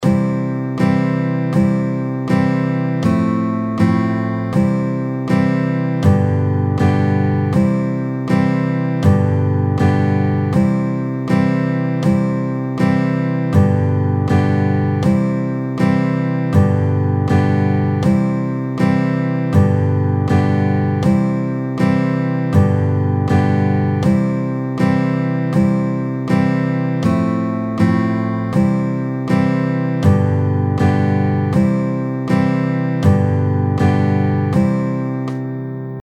キラキラ星_マイナスワン.mp3